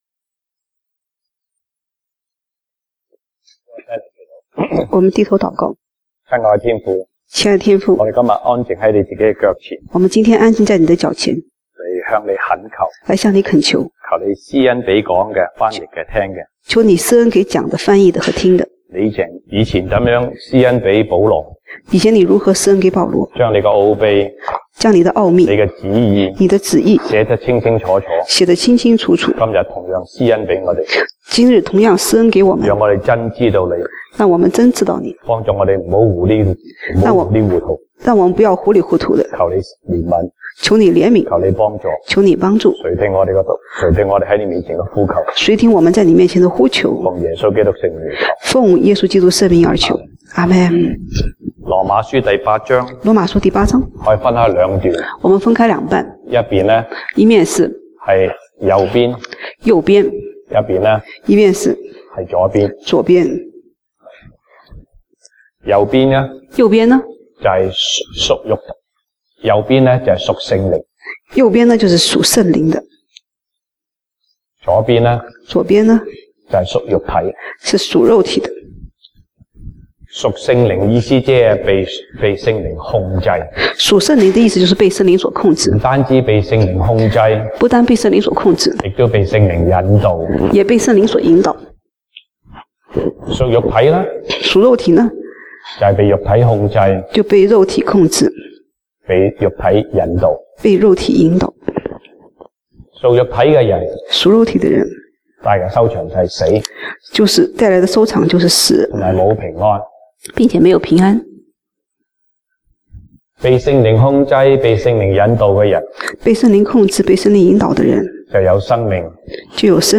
西堂證道 (英語) Sunday Service English: Mountain Top Experience